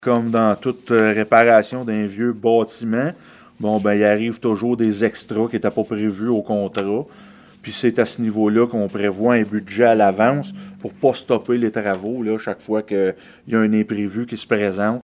La maire de Gracefield, Mathieu Caron, explique pourquoi il est important de prévoir ce montant à l’avance :